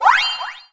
spin_attack.ogg